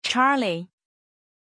Pronunciation of Charly
pronunciation-charly-zh.mp3